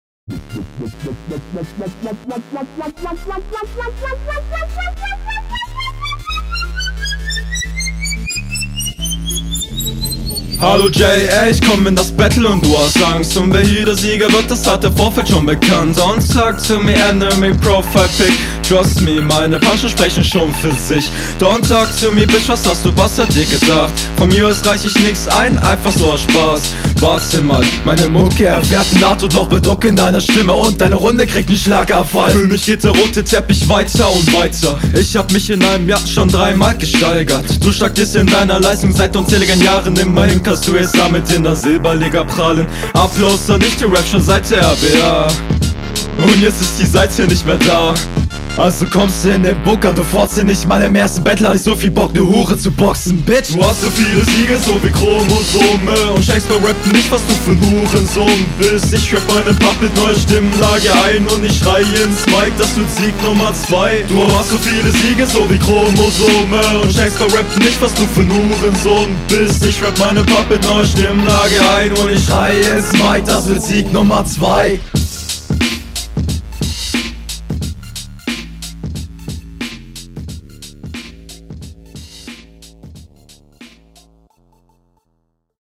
Am besten gefielen mir in der Runde die Betonungen. Stimmlich schön variiert.